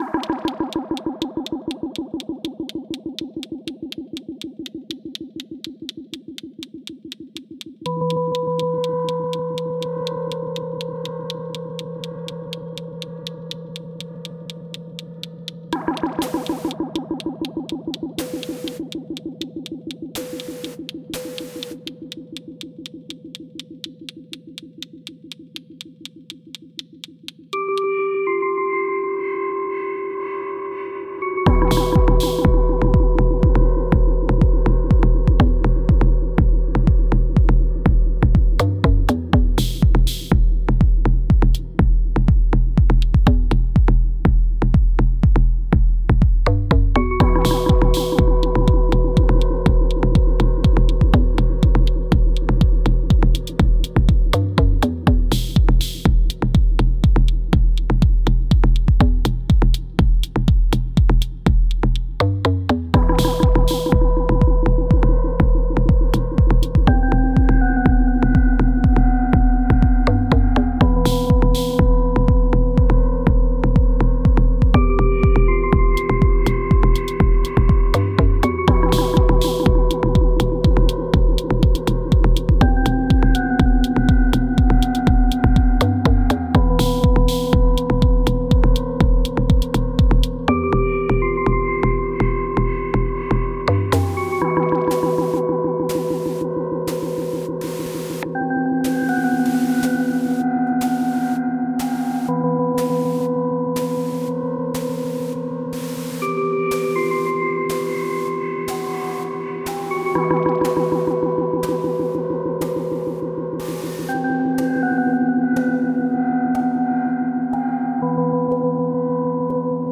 i wanted to upload a couple of older Cycles jams on here, just for fun :slight_smile: